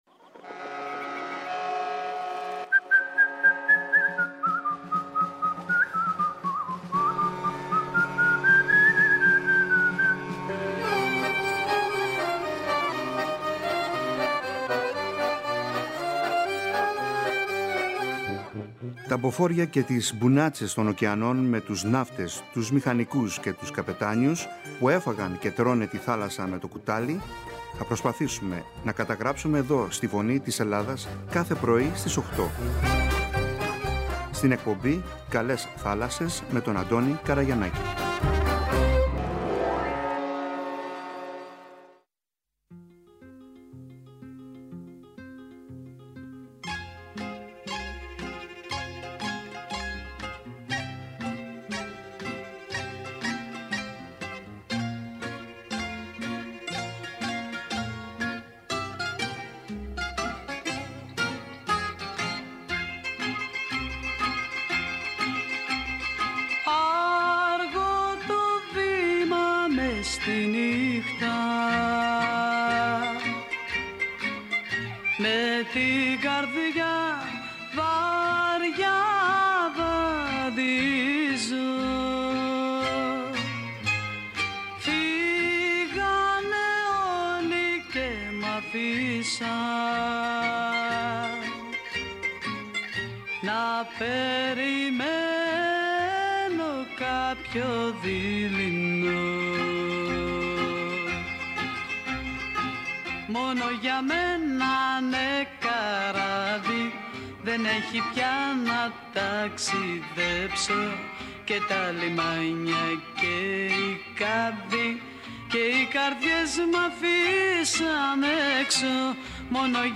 «Η Φωνή της Ελλάδας», το παγκόσμιο Ραδιόφωνο της ΕΡΤ, συμμετείχε, για πρώτη φορά, με δικό της περίπτερο στη σημαντικότερη έκθεση Ναυτιλίας «Ποσειδώνια 2024», από τη Δευτέρα 3 Ιουνίου έως και την Παρασκευή 7 Ιουνίου 2024.
Το δίωρο ραδιοφωνικό πρόγραμμα είχε συνεντεύξεις, παρουσιάσεις, χαιρετισμούς και μηνύματα από εκθέτες, φορείς, επισκέπτες, ανθρώπους της Ναυτιλίας και της θάλασσας.